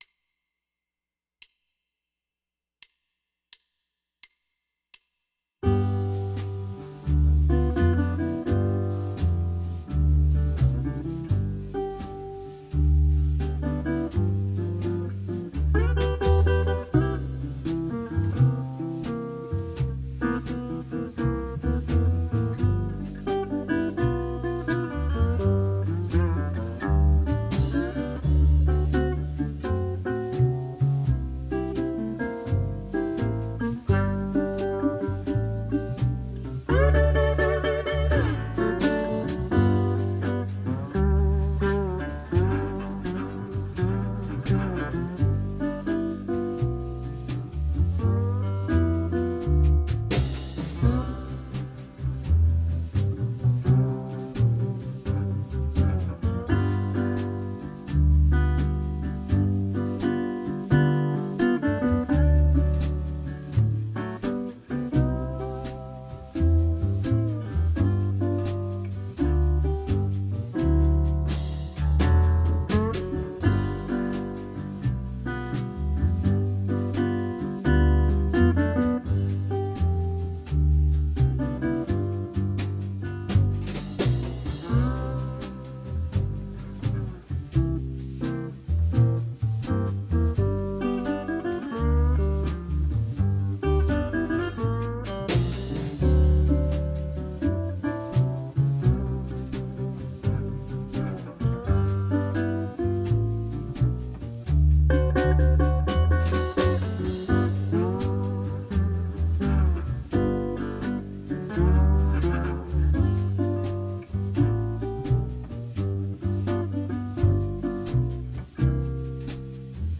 Блюзовый минус